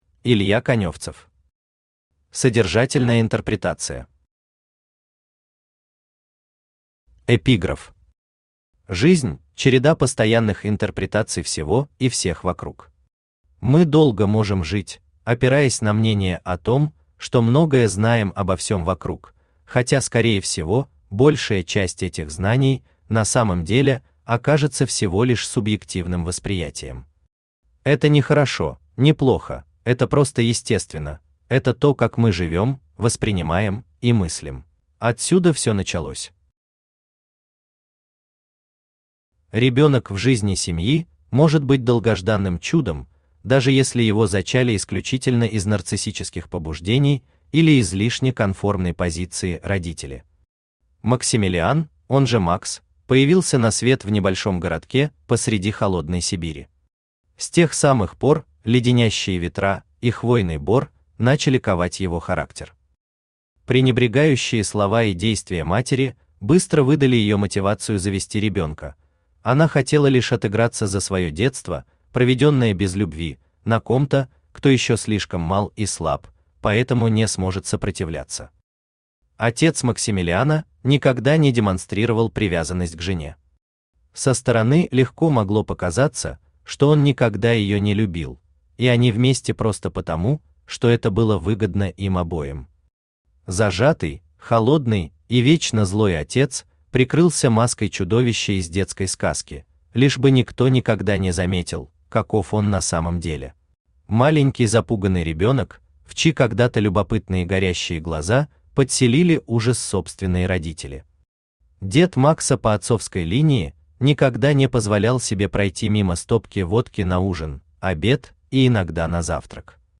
Аудиокнига Содержательная интерпретация | Библиотека аудиокниг
Aудиокнига Содержательная интерпретация Автор Илья Владимирович Коневцев Читает аудиокнигу Авточтец ЛитРес.